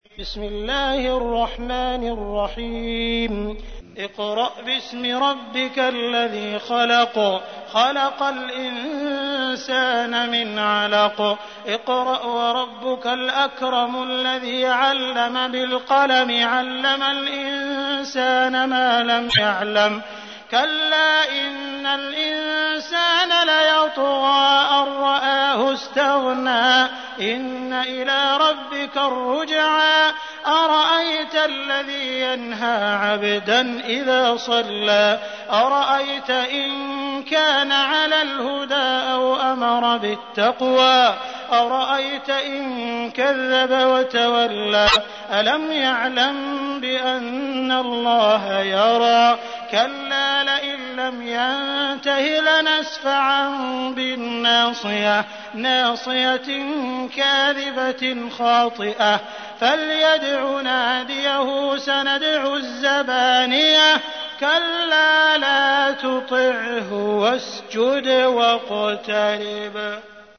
تحميل : 96. سورة العلق / القارئ عبد الرحمن السديس / القرآن الكريم / موقع يا حسين